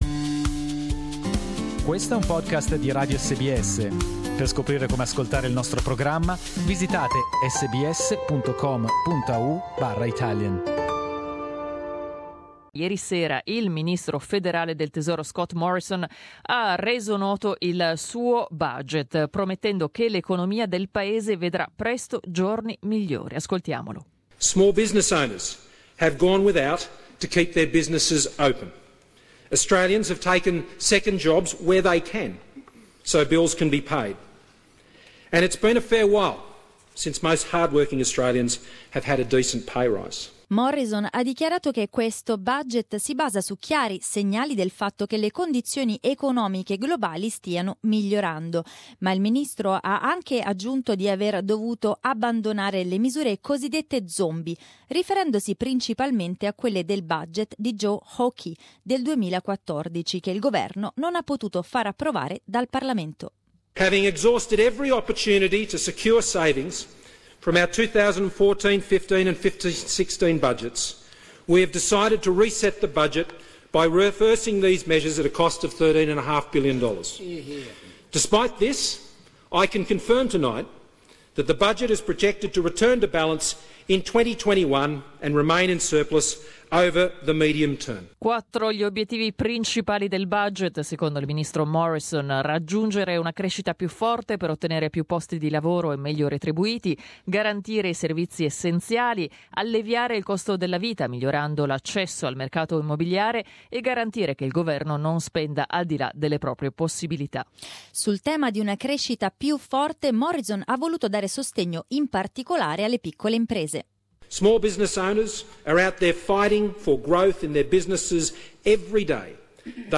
All the details in oiur report.